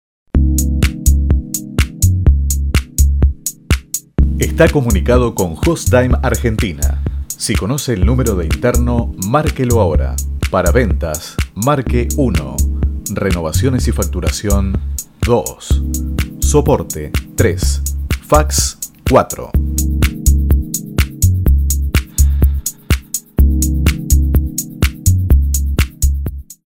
Voz joven, agil y dinamica para potenciar su marca o proyecto !!
Sprechprobe: eLearning (Muttersprache):
Voice over spanish